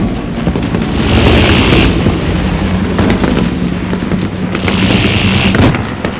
gunbattle3.wav